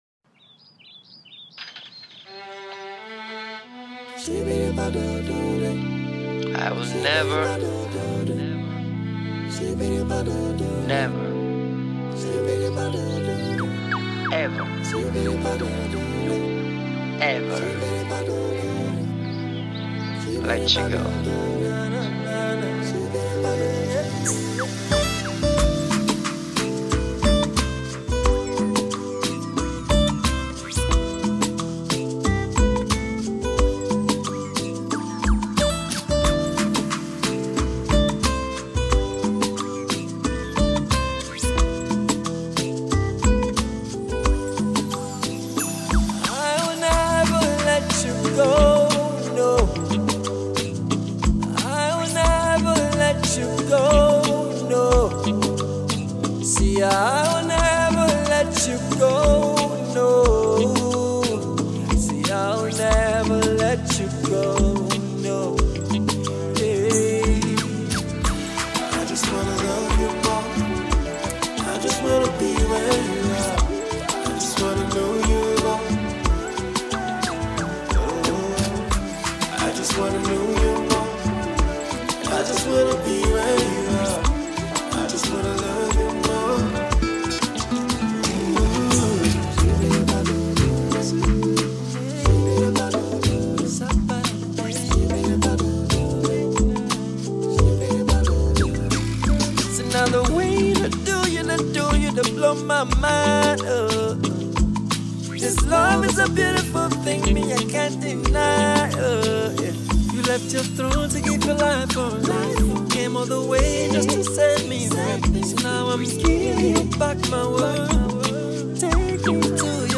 Afro-popAudioGospel